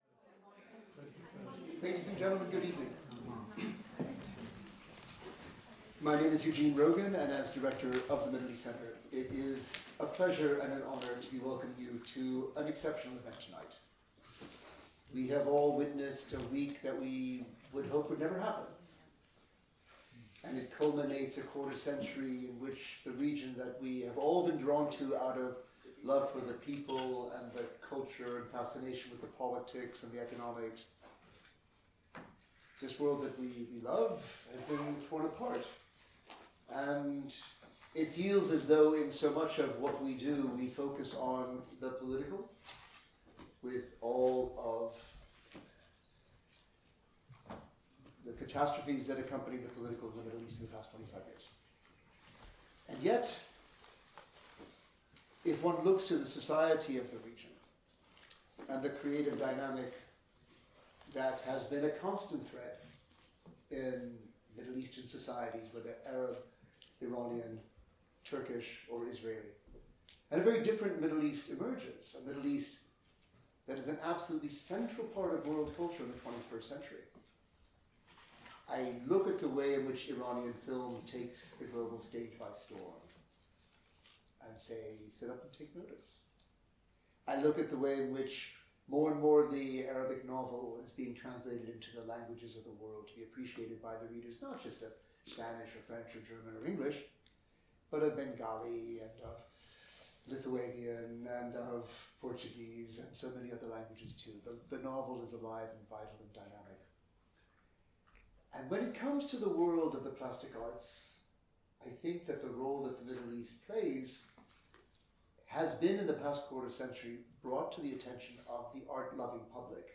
A keynote conversation